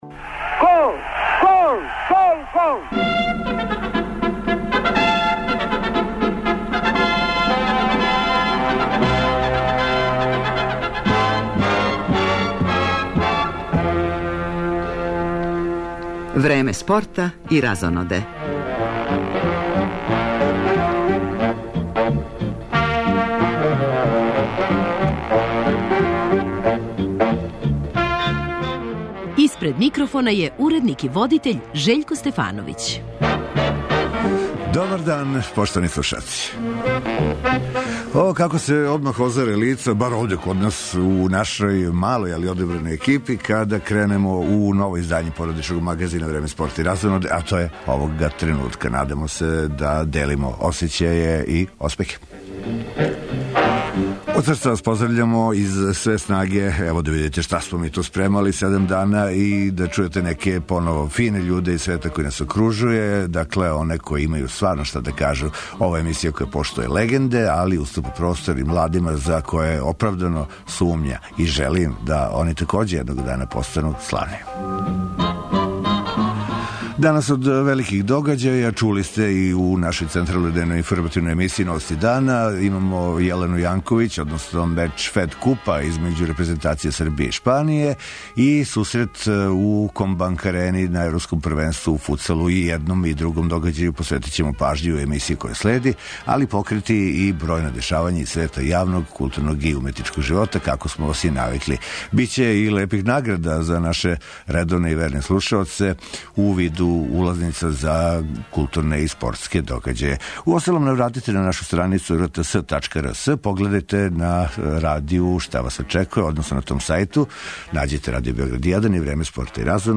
чућемо и селектора и неколико играча